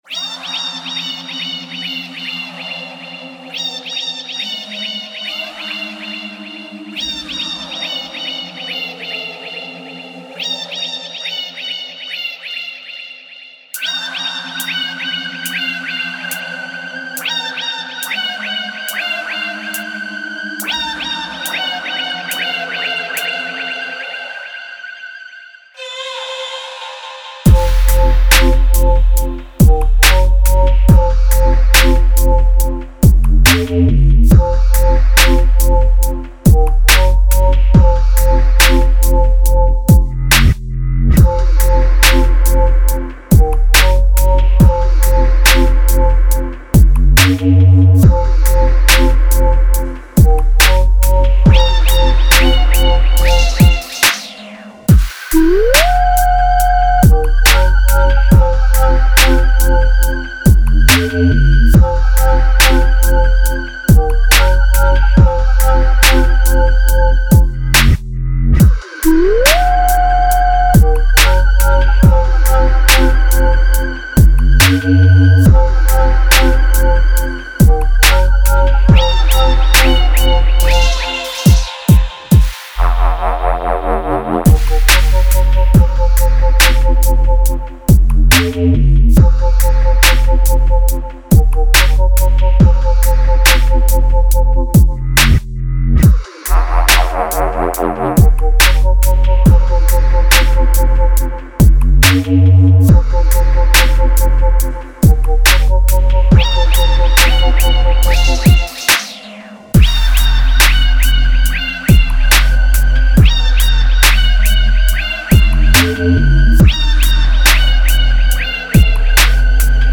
ベースやドラムサウンドに加えて、メロディやFXループも満載です。
デモサウンドはコチラ↓
Genre:Dubstep